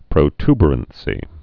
(prō-tbər-ən-sē, -ty-, prə-)